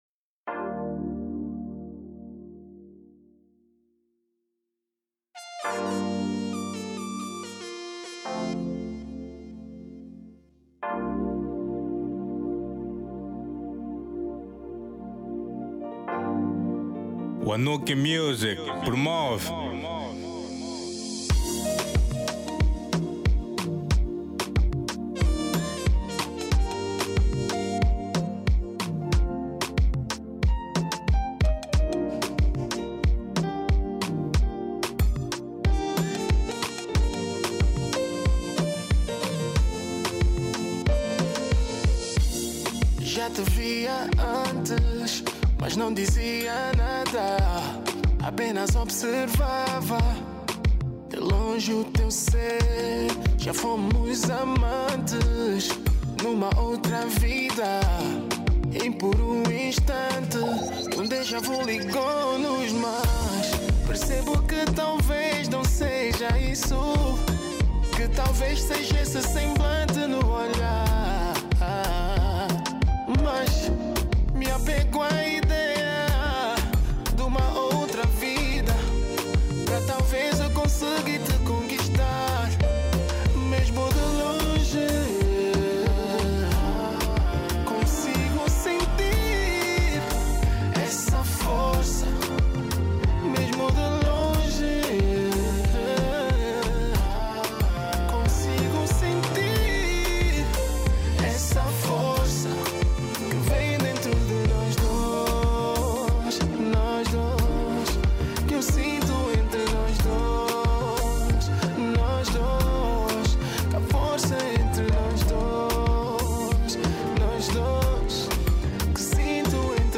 Genero: Kizomba